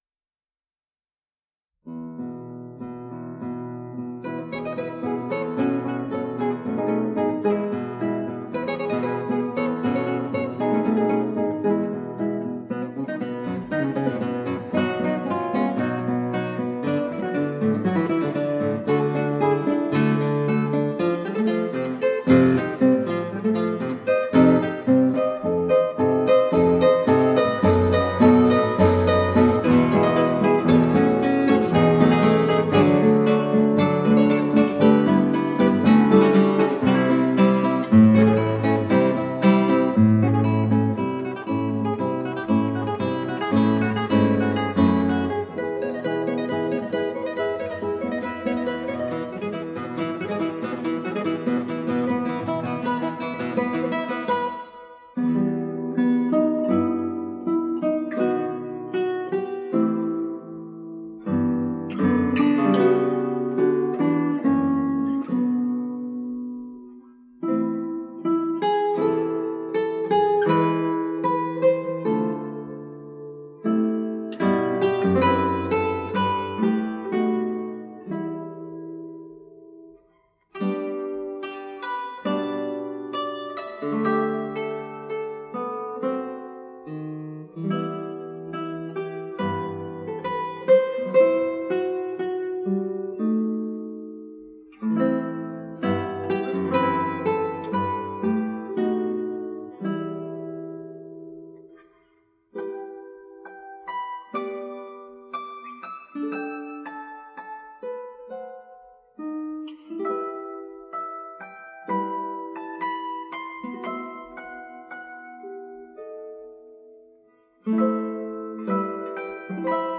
Instrumental
Classical Guitar